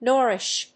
意味・対訳 ノーリッシュ； ノリッシュ